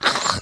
Minecraft Version Minecraft Version snapshot Latest Release | Latest Snapshot snapshot / assets / minecraft / sounds / mob / rabbit / attack2.ogg Compare With Compare With Latest Release | Latest Snapshot